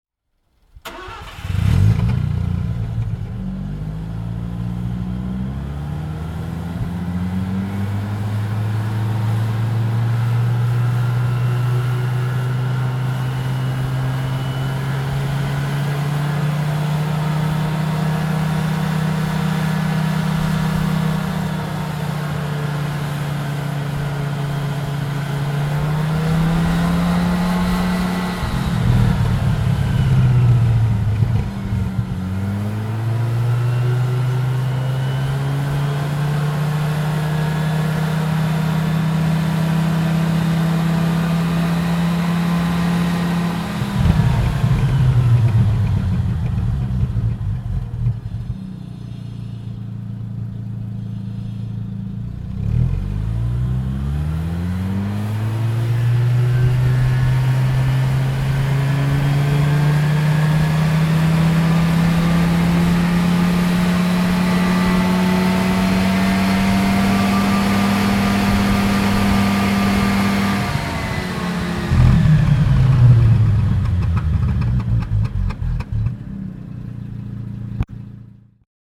Mazda RX-7 (1979) - Starten, Leerlauf, Hochdrehen
Mazda_RX-7_1979.mp3